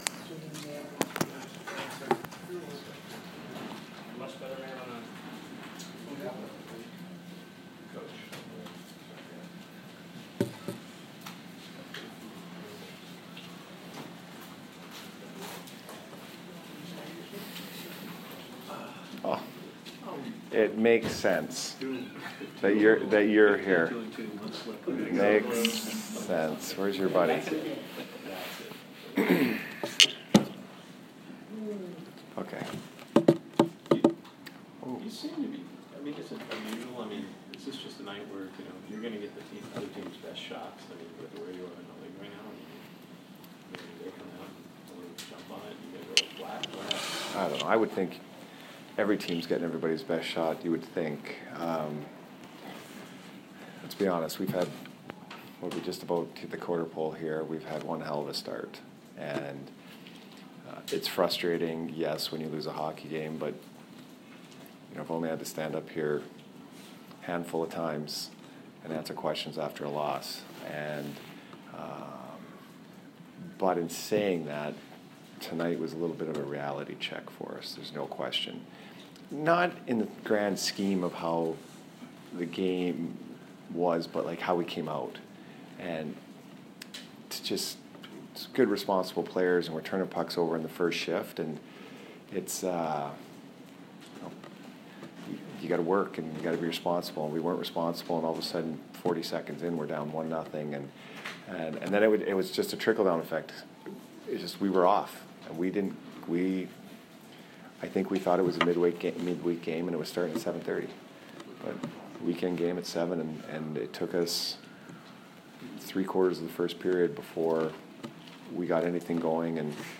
Head Coach Jon Cooper Post-Game 11/18